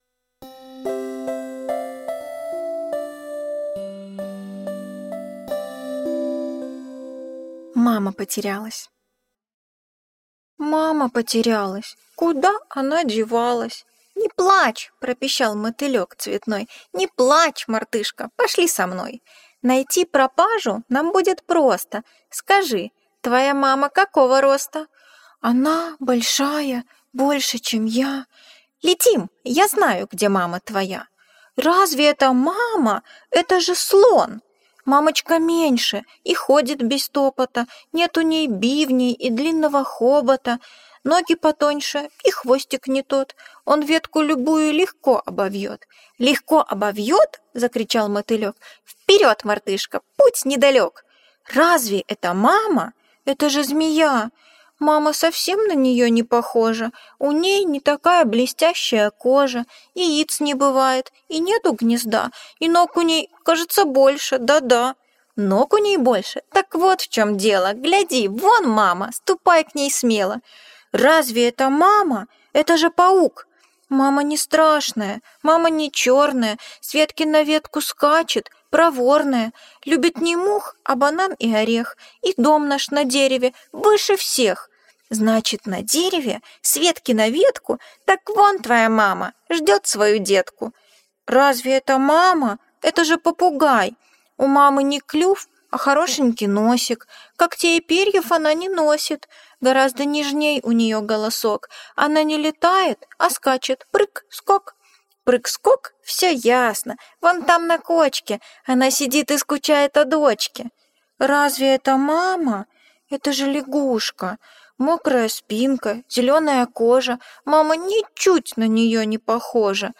Хочу к маме - аудиосказка Джулии Дональдсон - слушать онлайн